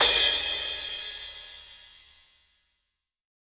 VEC3 Crash